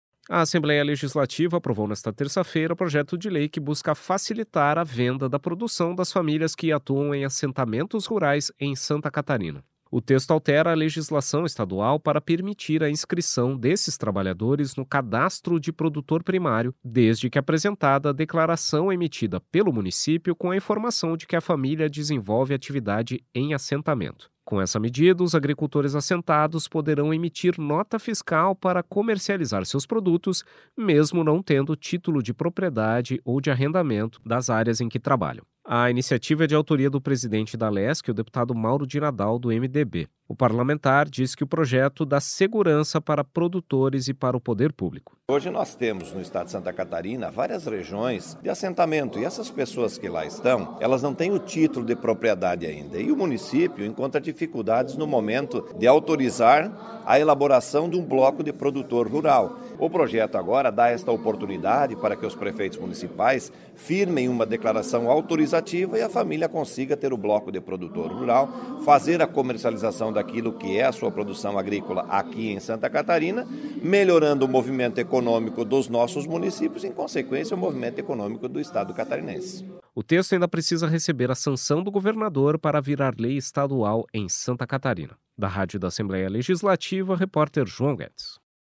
Entrevista com:
- deputado Mauro de Nadal (MDB), autor do projeto de lei.